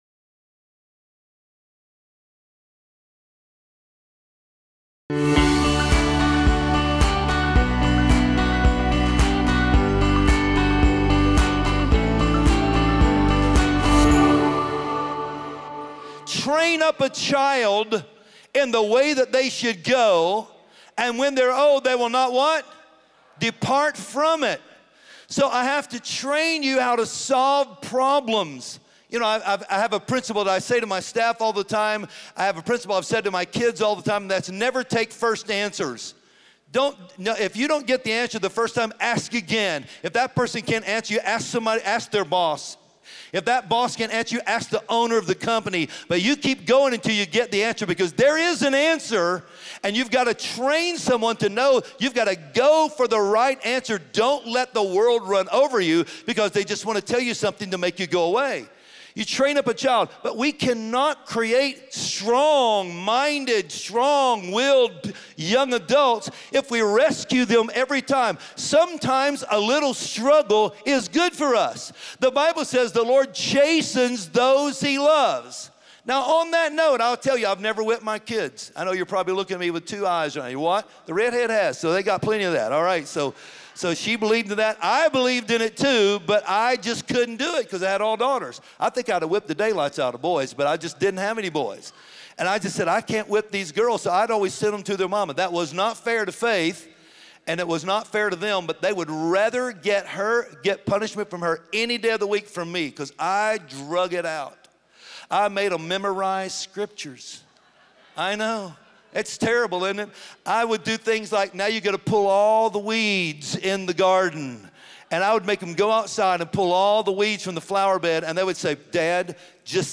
Join us this week for the sermon “Set the Standard.”